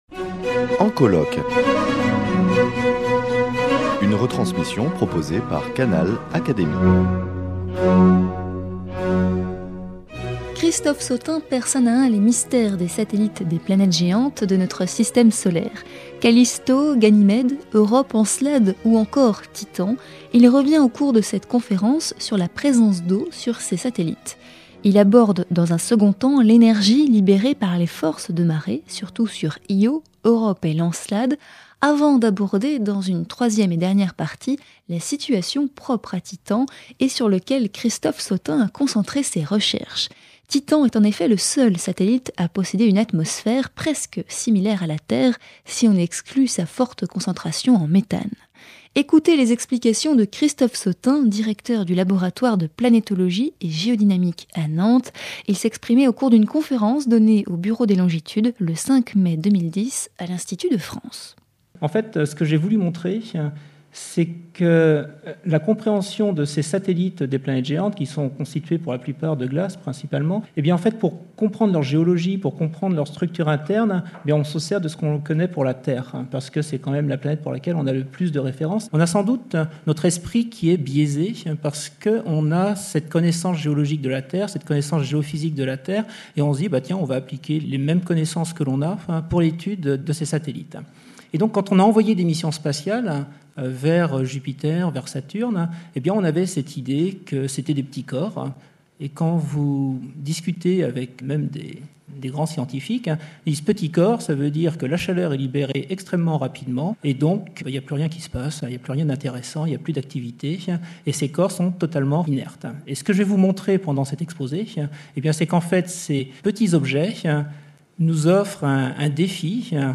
Canal Académie vous propose d’écouter la retransmission de cette conférence du Bureau des longitudes.